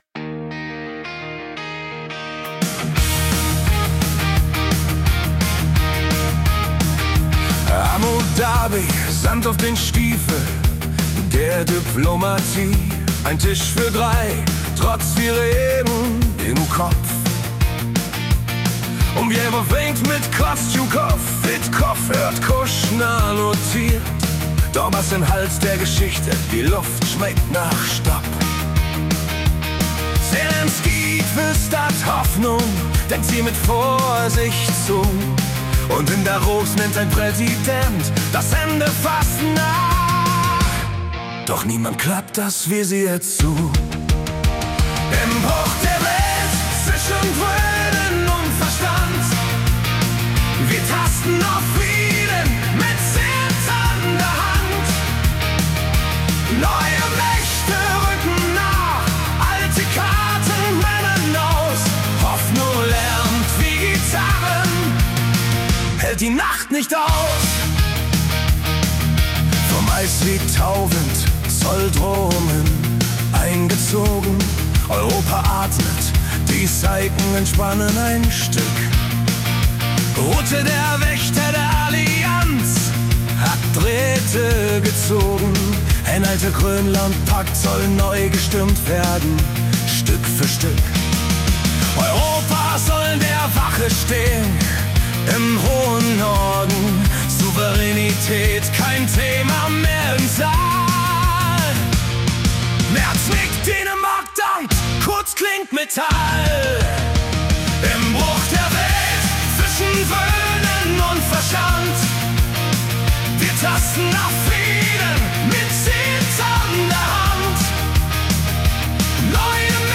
Die Nachrichten vom 24. Januar 2026 als Rock-Song interpretiert.